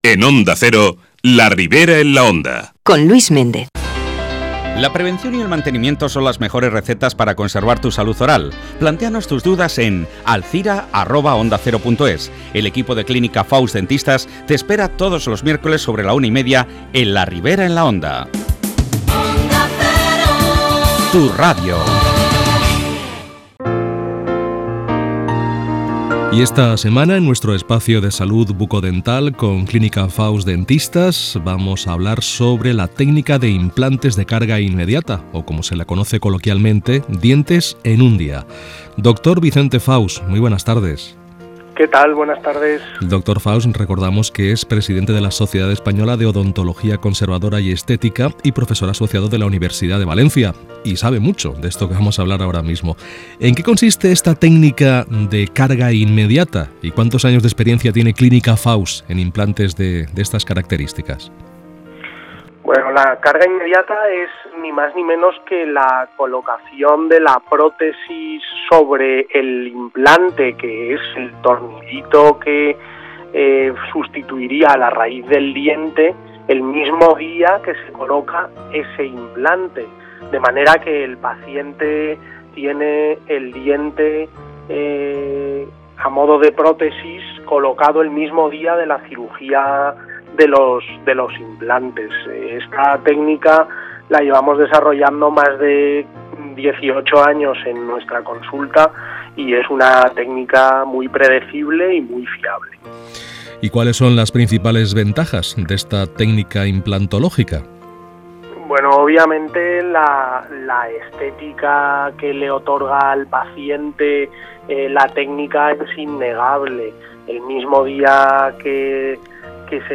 Entrevistas Onda Cero Alzira